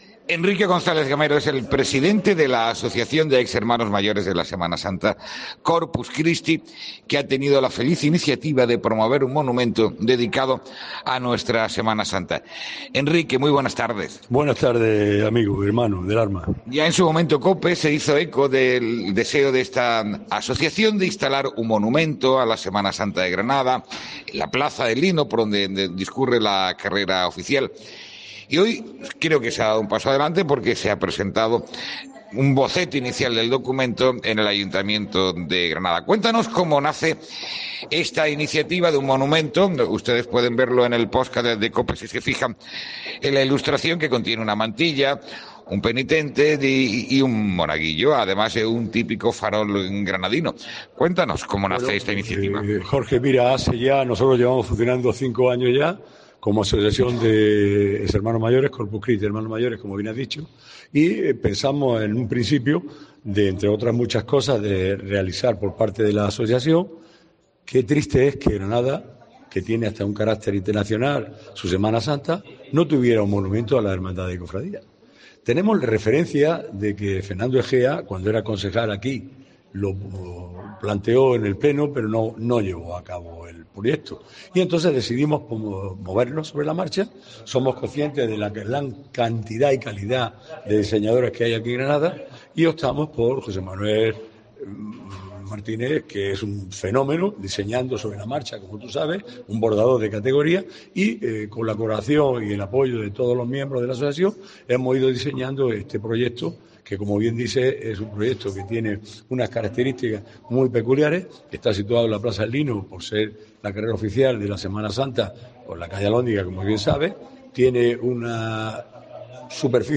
ENTREVISTA|| La Asociación Corpus Christi presenta el monumento a la Semana Santa que dio a conocer COPE